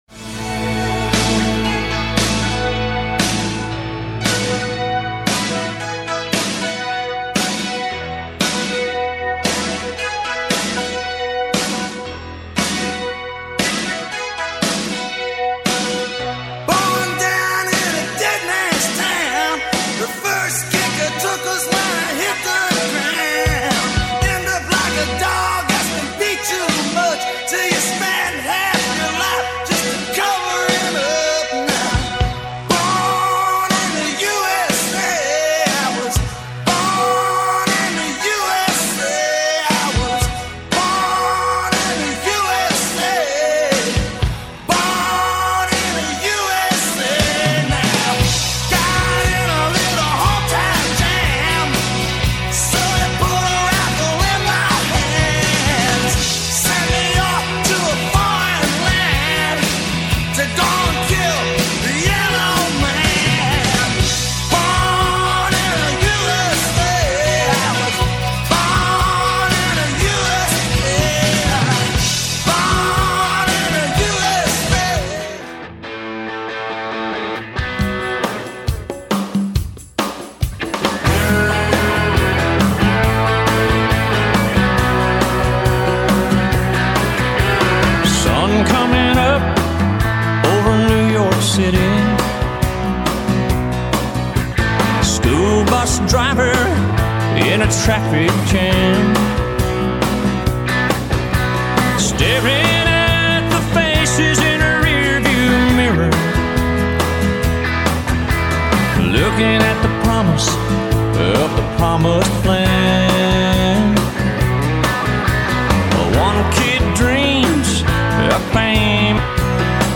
Click link at 9 pm on the dot on July 4th to hear the passionately patriotic music used to choreograph this year's fireworks display!